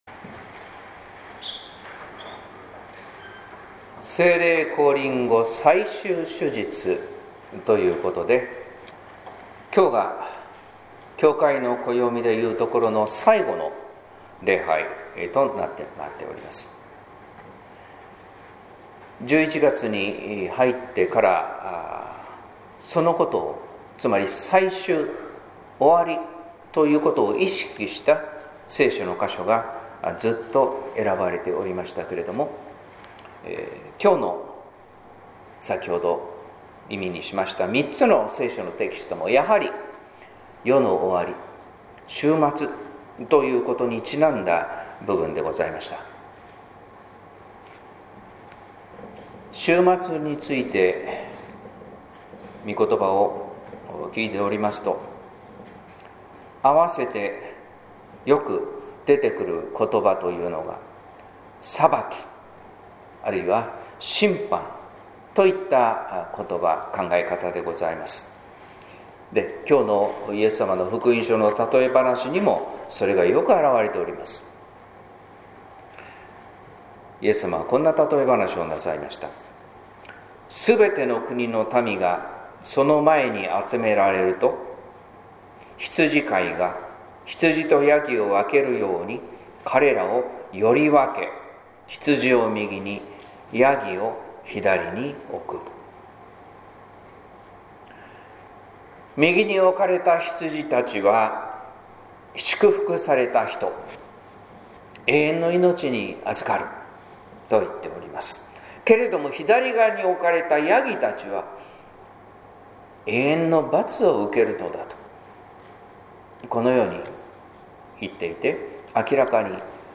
説教「最も小さき救い主」（音声版） | 日本福音ルーテル市ヶ谷教会